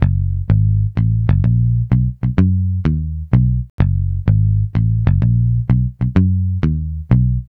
Swingerz 5 Bass-F#.wav